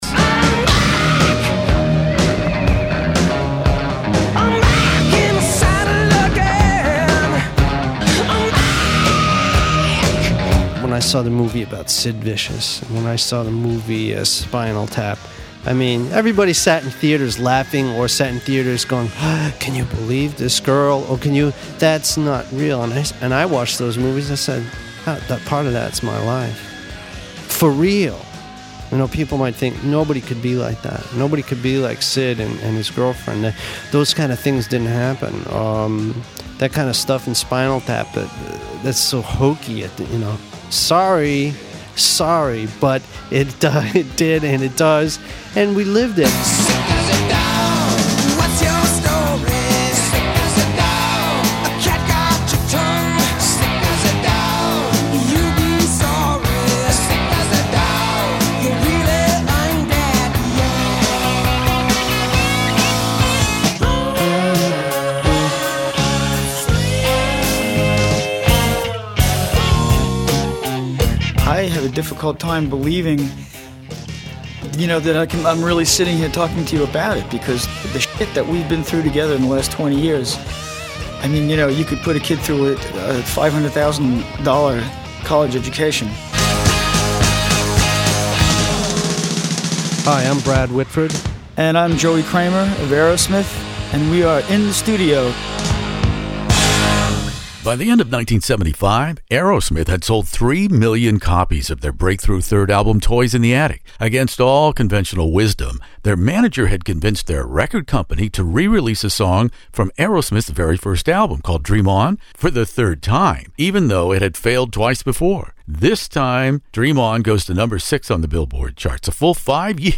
Aerosmith "Rocks" interview with Joe Perry In the Studio
This is not the first time in the band’s fifty+ years that Aerosmith has been on hiatus, Joe Perry took time for this classic rock interview to discuss the challenges of maintaining the same personnel over the long career arc of this seminal American band.